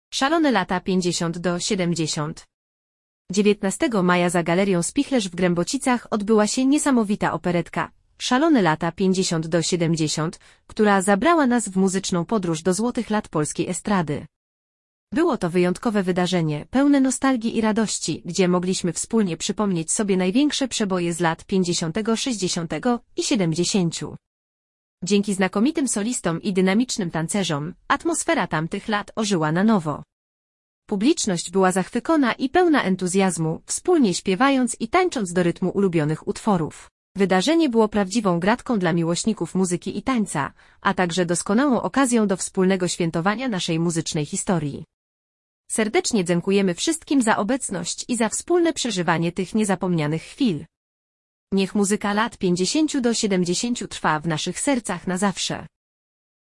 Było to wyjątkowe wydarzenie, pełne nostalgii i radości, gdzie mogliśmy wspólnie przypomnieć sobie największe przeboje z lat 50., 60. i 70.
Dzięki znakomitym solistom i dynamicznym tancerzom, atmosfera tamtych lat ożyła na nowo. Publiczność była zachwycona i pełna entuzjazmu, wspólnie śpiewając i tańcząc do rytmu ulubionych utworów.